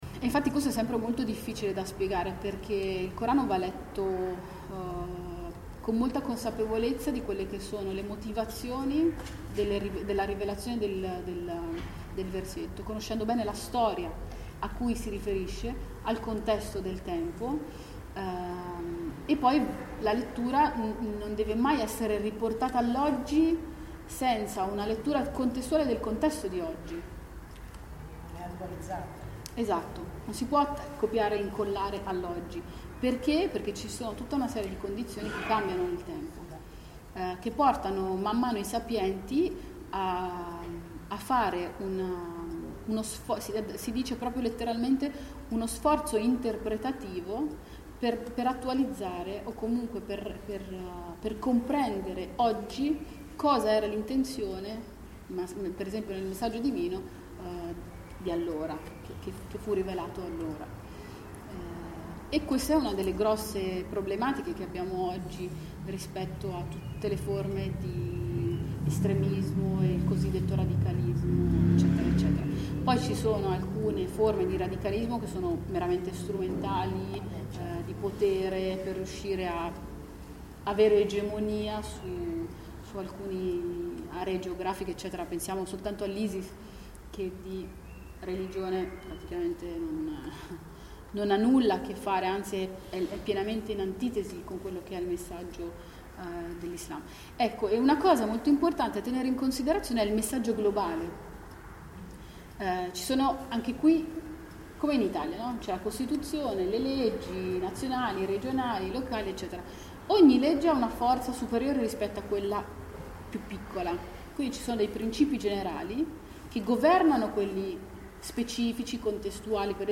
sabato 13 maggio 2017 visita alla moschea di via padova a milano.